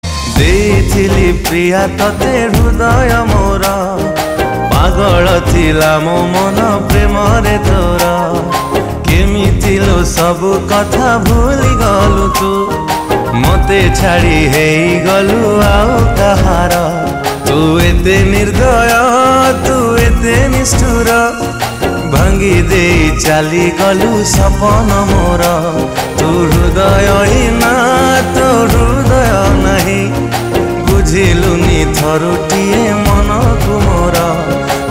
Odia Ringtones
sad song